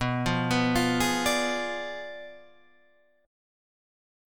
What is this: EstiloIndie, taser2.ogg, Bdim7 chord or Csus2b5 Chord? Bdim7 chord